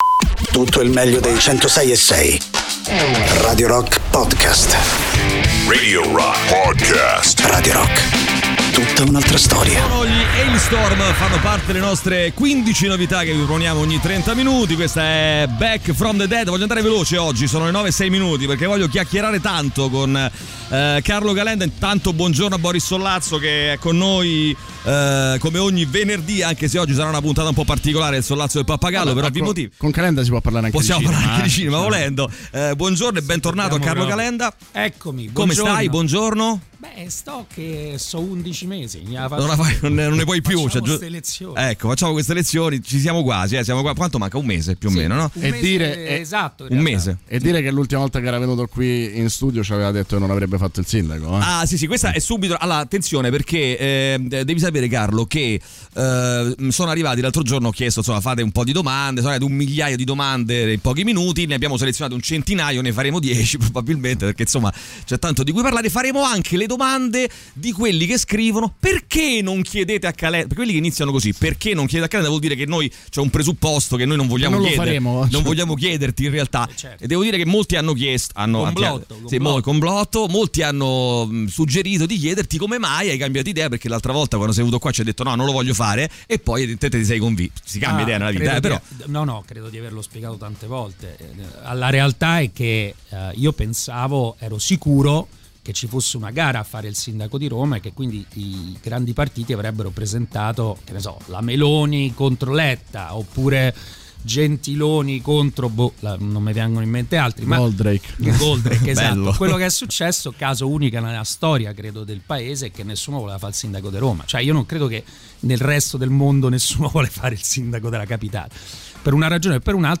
Interviste: Carlo Calenda (03-09-21)